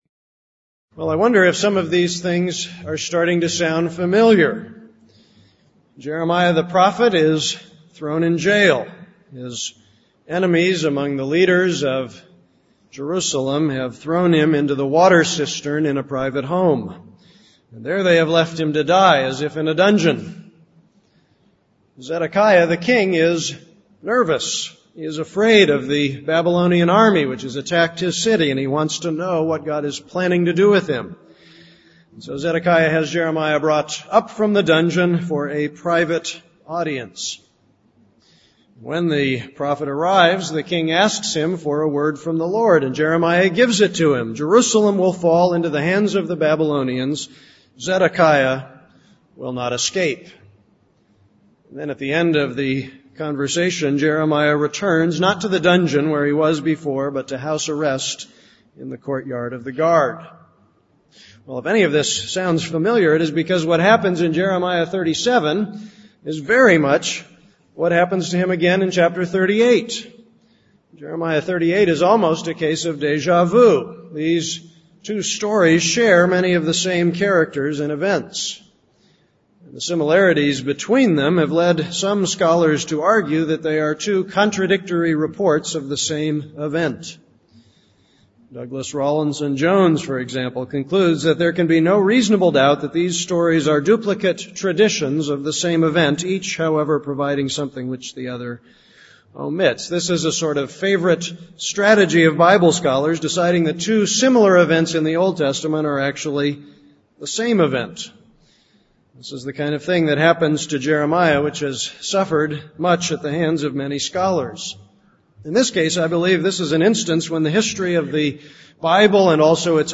This is a sermon on Jeremiah 38:14-28.